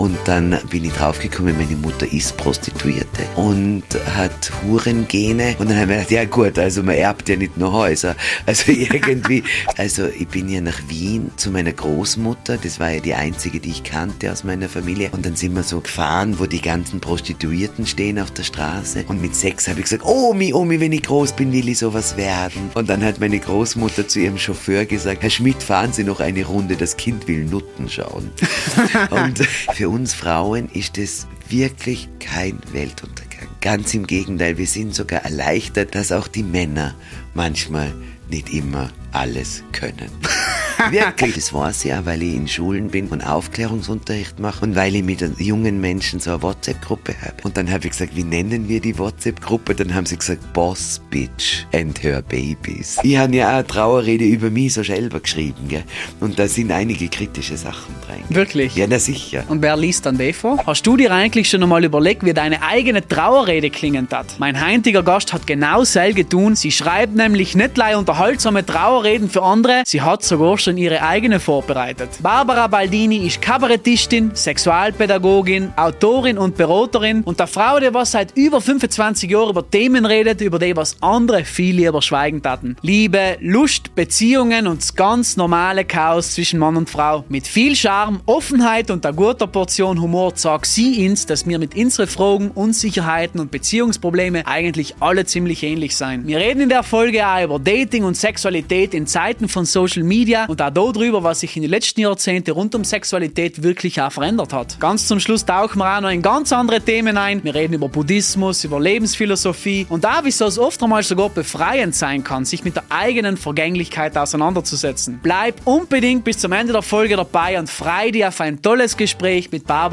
Höhepunkte und Begegnungen mit dem Tod | Das Gespräch